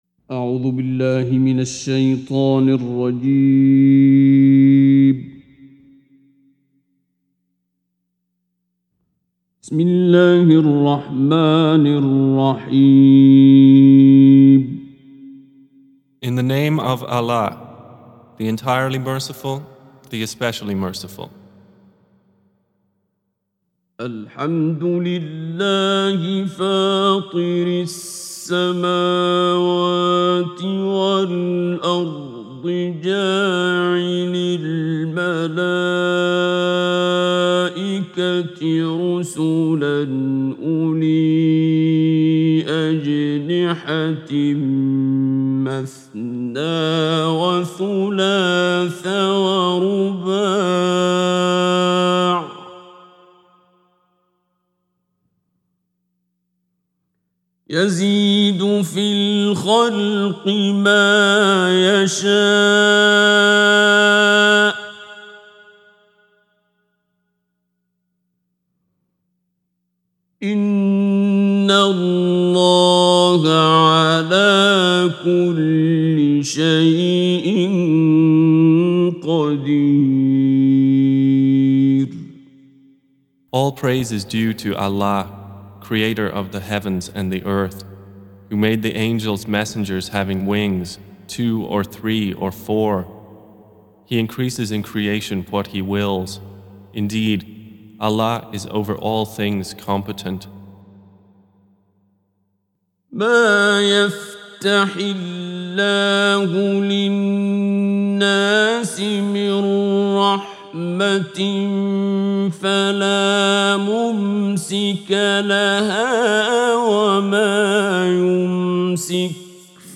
Surah Repeating تكرار السورة Download Surah حمّل السورة Reciting Mutarjamah Translation Audio for 35. Surah F�tir or Al�Mal�'ikah سورة فاطر N.B *Surah Includes Al-Basmalah Reciters Sequents تتابع التلاوات Reciters Repeats تكرار التلاوات